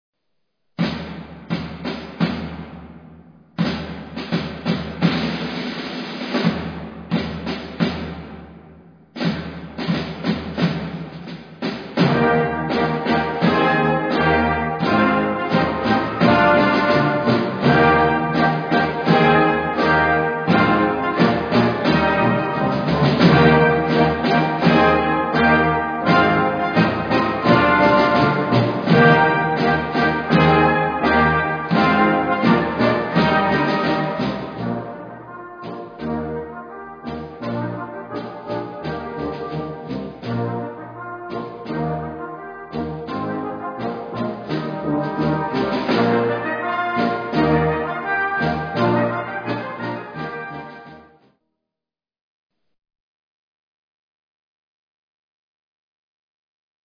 • Une suite facile mais fascinante en trois parties.